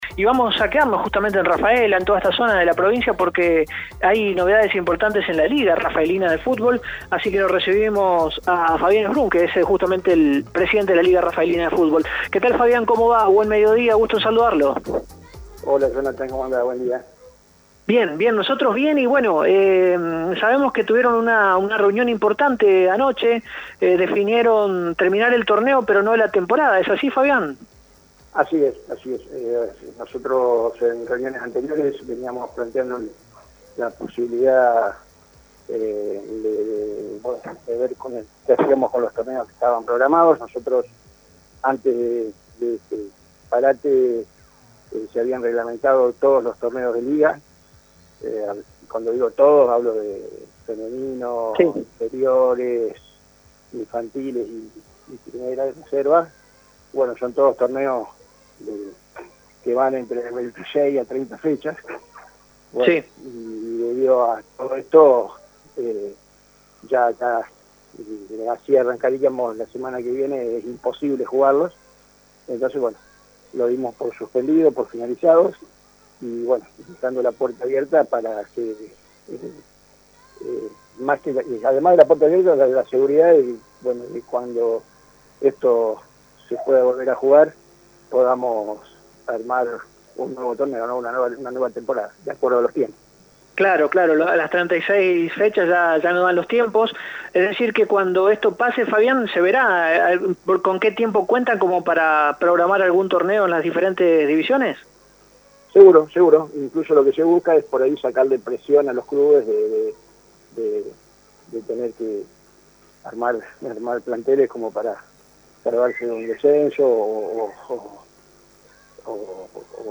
En dialogo con Radio EME Deportivo,